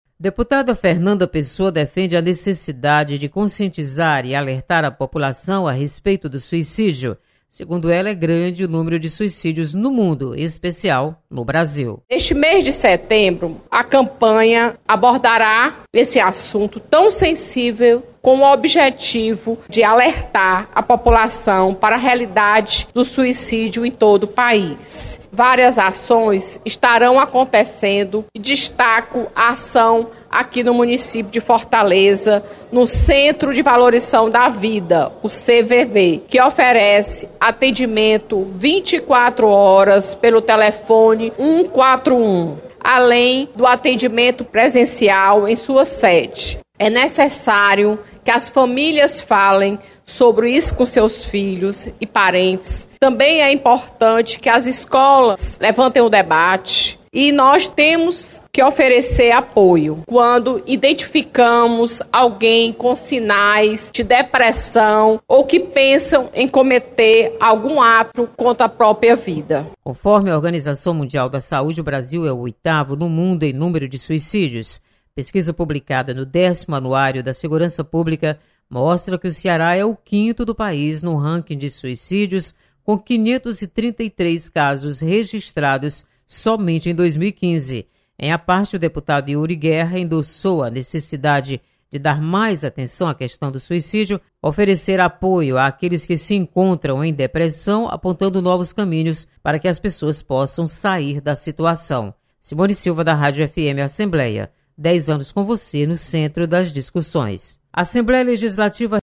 Deputada alerta para o aumento de suicídio no País. Repórter